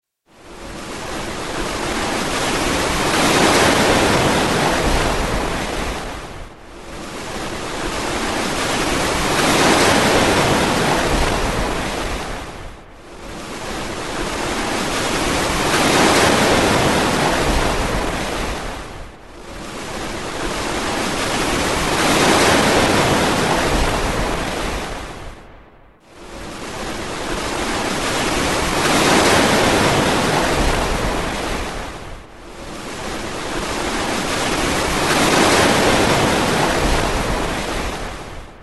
寄せては返す波の音には癒やしやリラックスの効果があります。